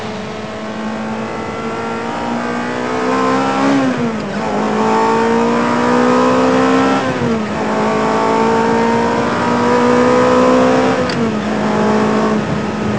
D      R       E       A       M       O       C       A       R       S      -     bruits de moteurs